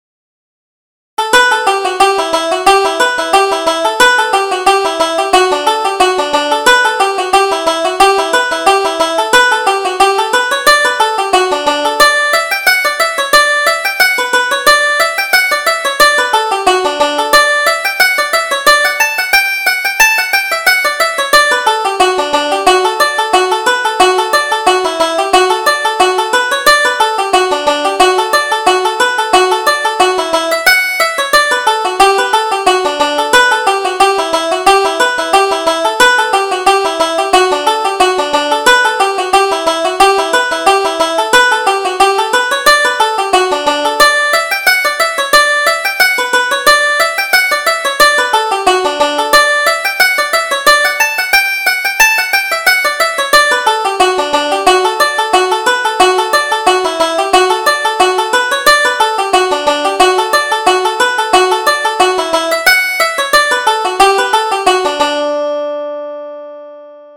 Reel: The Cup of Tea